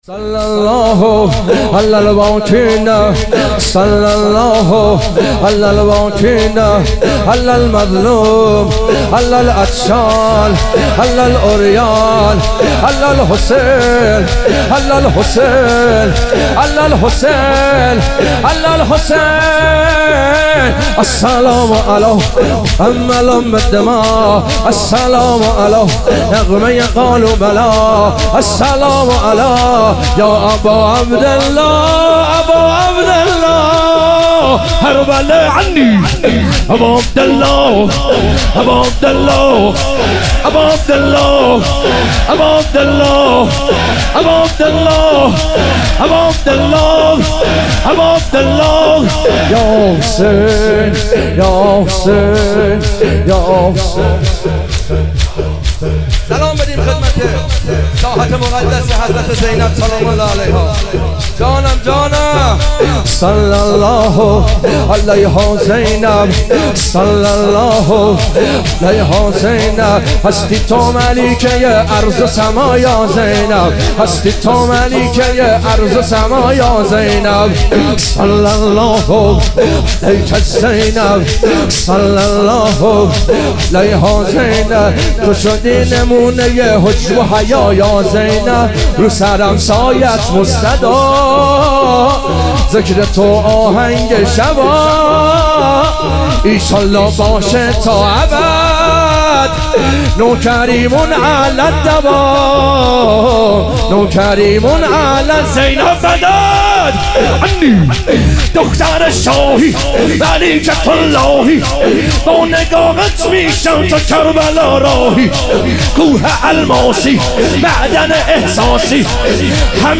ظهور وجود مقدس حضرت زینب علیها سلام - شور